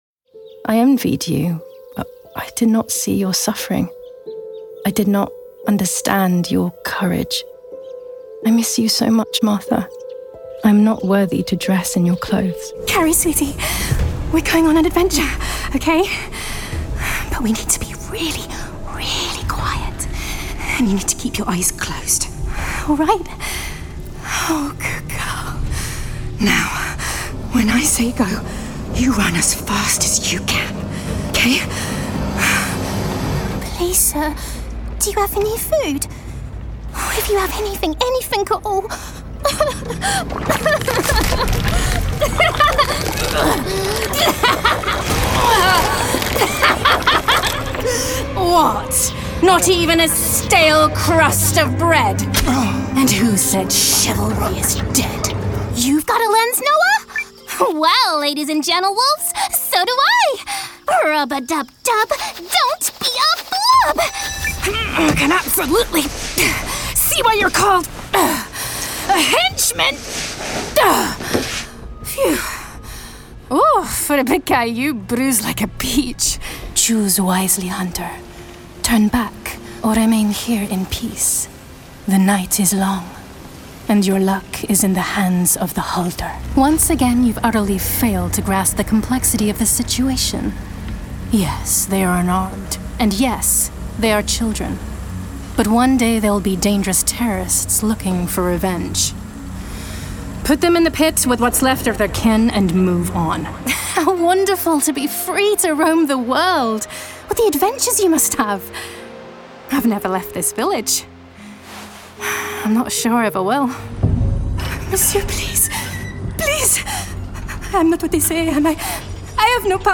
Female
British English (Native)
Bright, Character, Cool, Corporate, Engaging, Friendly, Natural, Reassuring, Soft, Warm, Versatile, Young
British English (RP and Neutral), Neutral English (Euro and Mid-Atlantic), Gen Am, Light French, Scottish (Endinburgh), Light Northern English (Lancashire), Misc-Scandinavian
A naturally warm, natural and fresh 20-30s tone and a native neutral British accent.
Microphone: Orpheus by Sontronics
Audio equipment: Universal Audio Apollo Twin soundcard, acoustically treated vocal booth, Beyerdynamic DT770 Pros (80 OHM) headphones, Macbook.